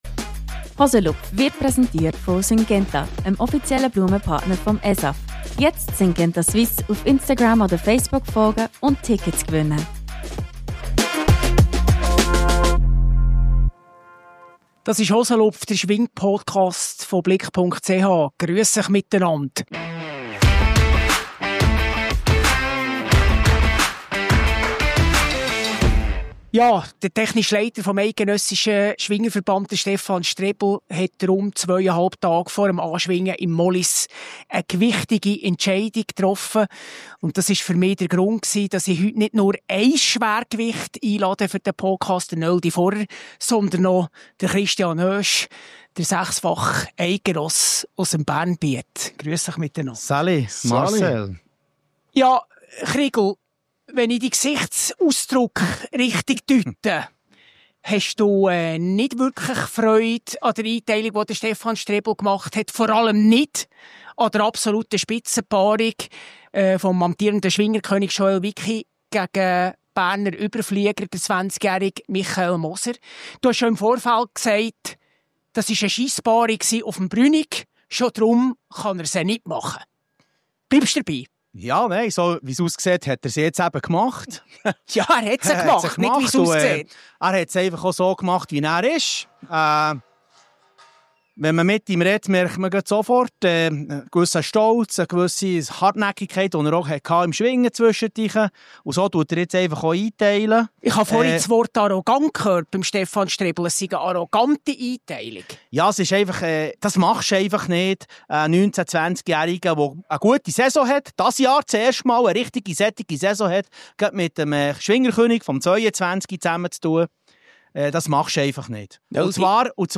live vom ESAF-Gelände aus Mollis